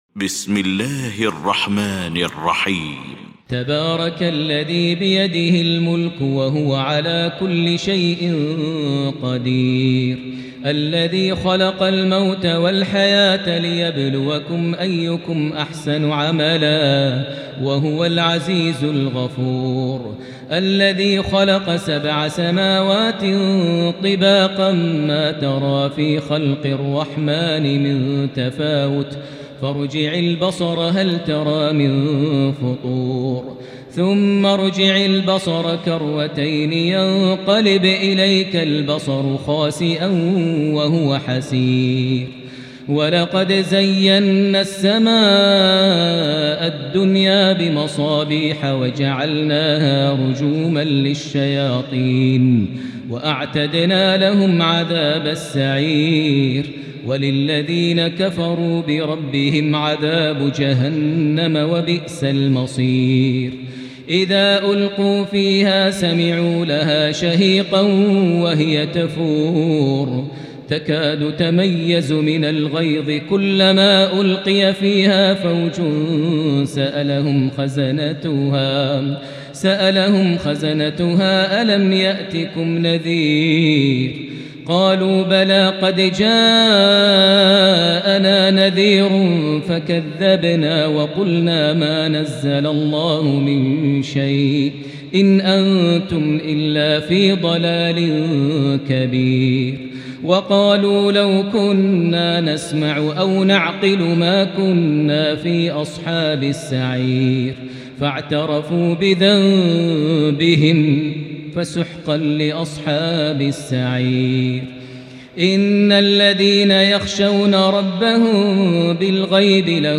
المكان: المسجد الحرام الشيخ: فضيلة الشيخ ماهر المعيقلي فضيلة الشيخ ماهر المعيقلي الملك